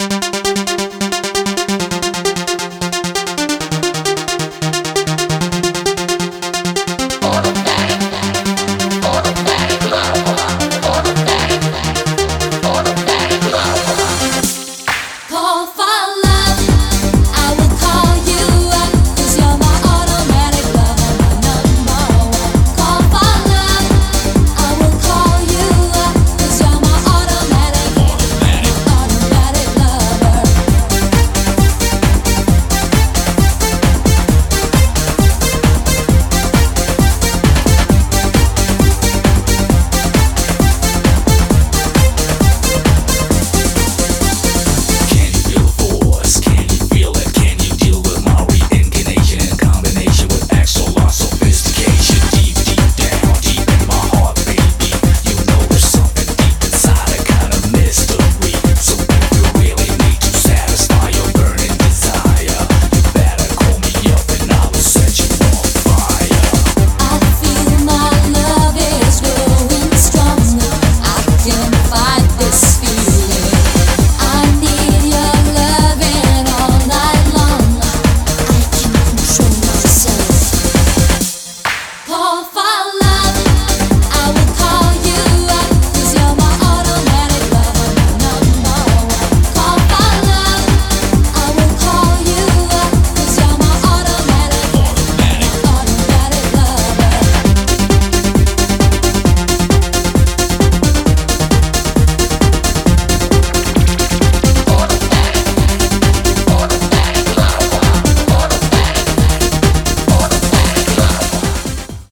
BPM133
Audio QualityPerfect (High Quality)
I ripped the music straight from the original album CD.